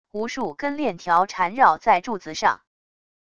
无数根链条缠绕在柱子上wav音频